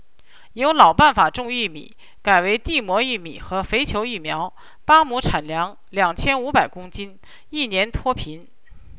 M: Male, F: Female
Vocoded GMM VAE